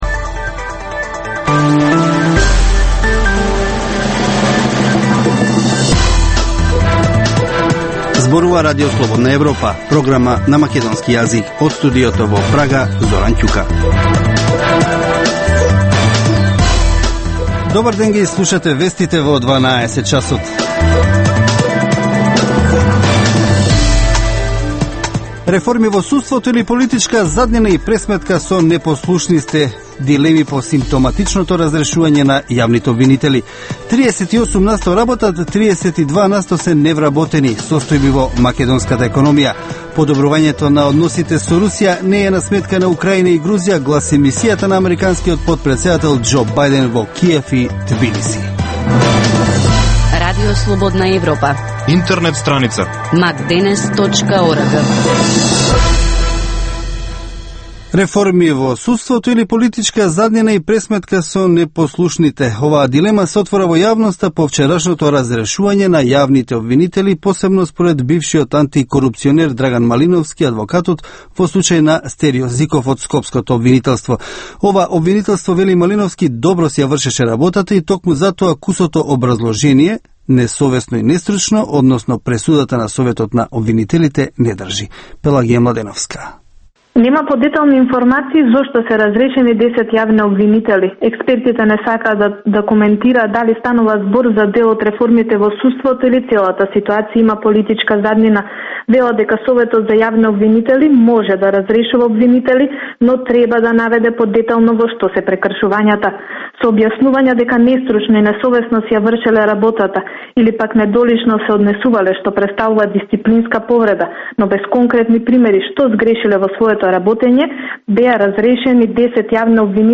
Информативна емисија, секој ден од Студиото во Прага. Топ вести, теми и анализи од Македонија, регионот и светот. Во Вестите во 12 часот доминантни се актуелните теми од политиката и економијата.